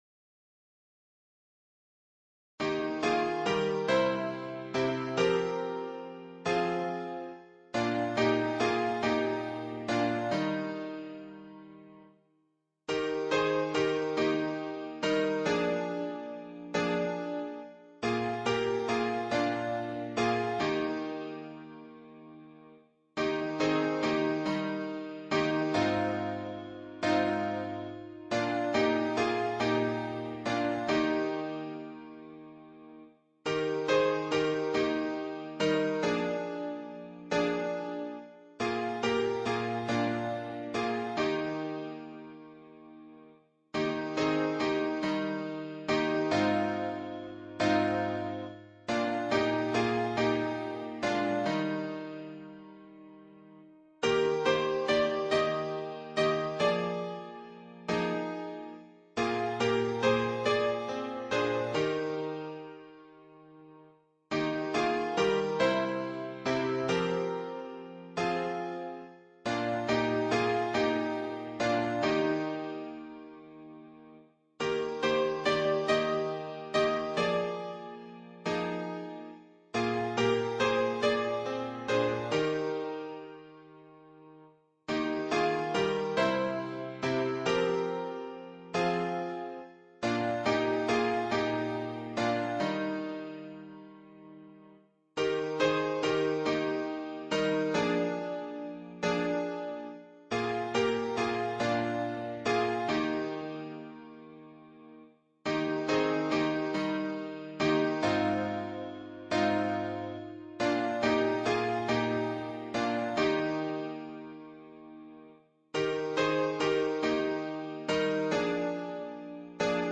伴奏
原唱